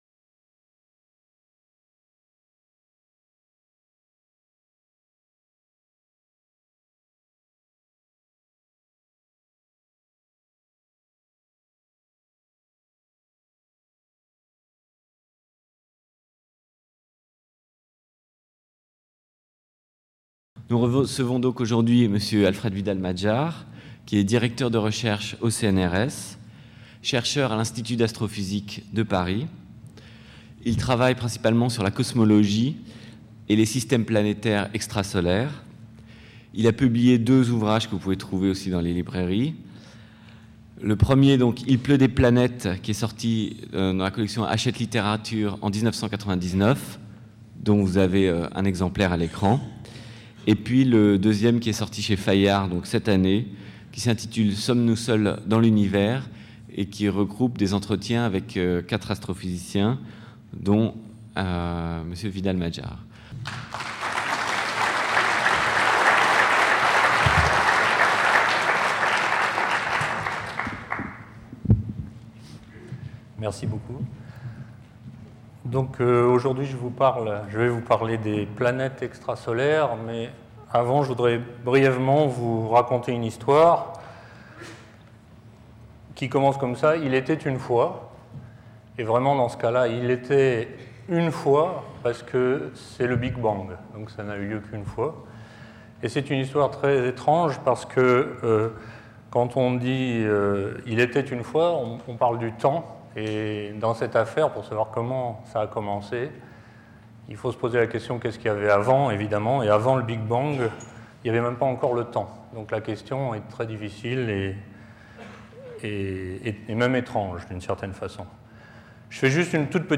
La conférence fera le point sur ces découvertes, et donnera quelques conséquences éventuelles sur la possibilité de trouver la vie ailleurs dans l'univers. Le rôle du temps sera tout particulièrement souligné.